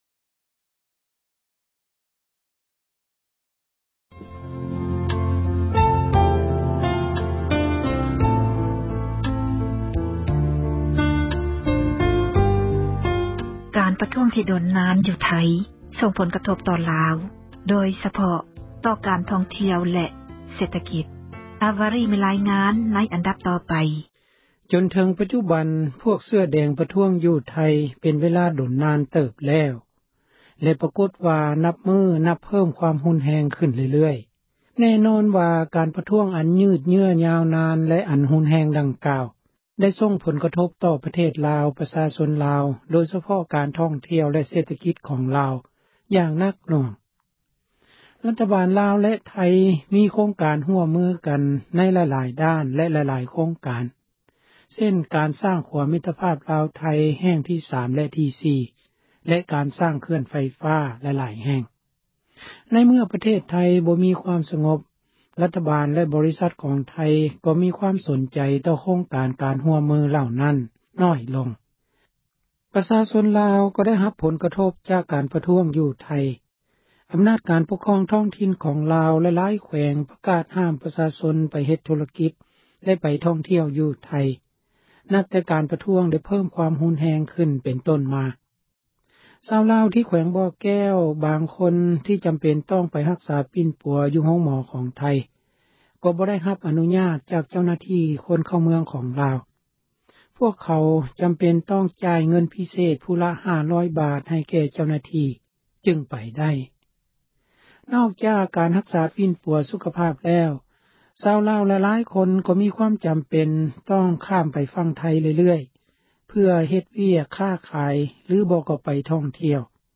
ການປະທ້ວງ ທີ່ດົນນານ ຢູ່ໄທ — ຂ່າວລາວ ວິທຍຸເອເຊັຽເສຣີ ພາສາລາວ